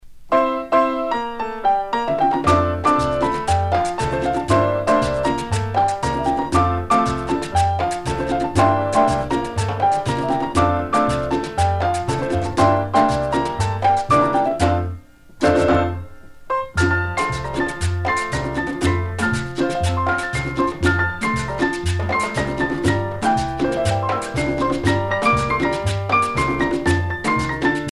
danse : merengue